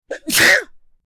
Sneeze
Sneeze.mp3